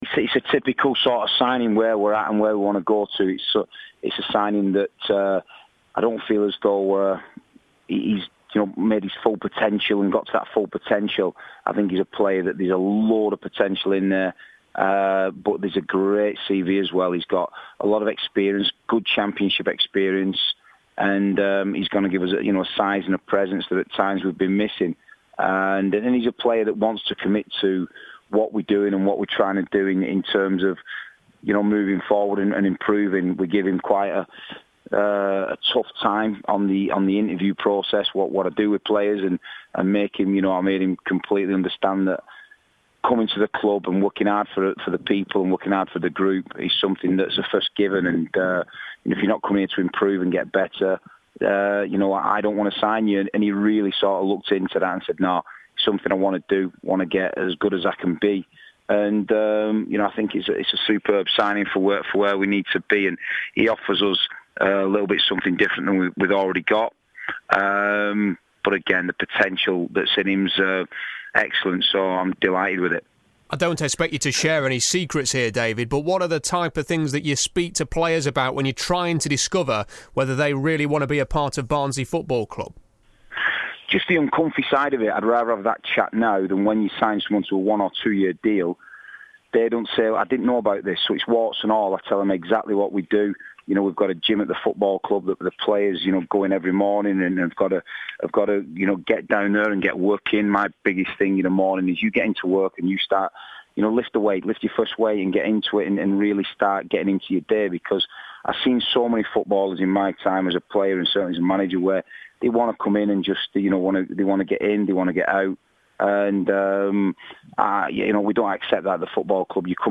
Today's interview